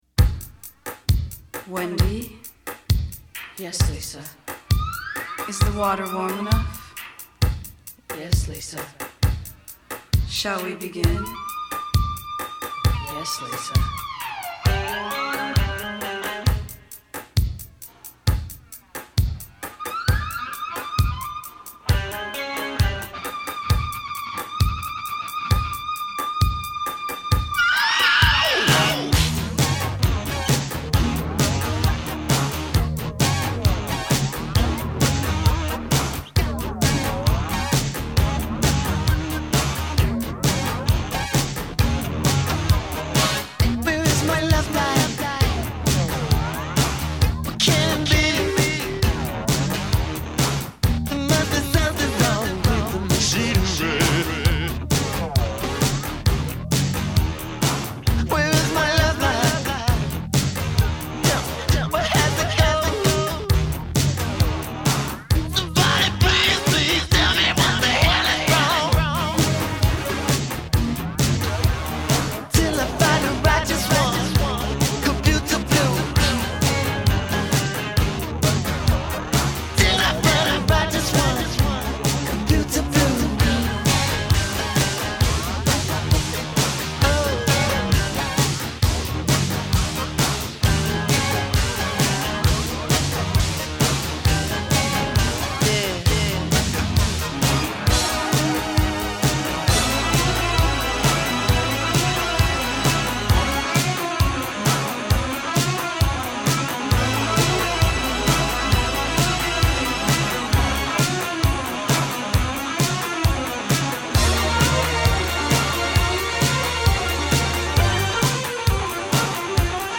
Posted by on February 17, 2014 in Soul/R&B and tagged , , .